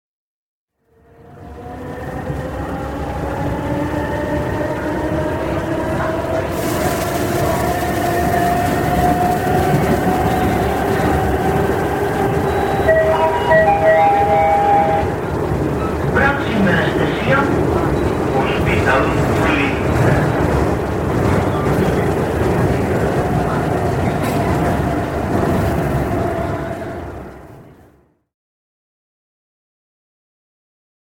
KEfd6BFVtSZ_metro-6-Barcelona.mp3